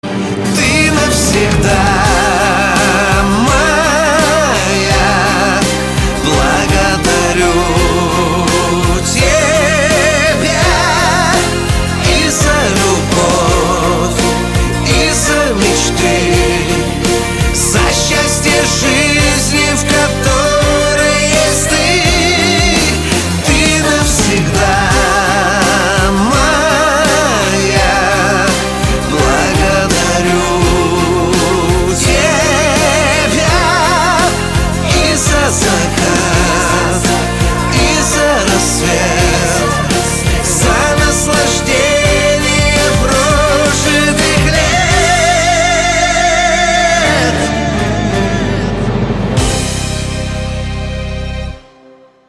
Рингтоны на Любимую, Шансон рингтоны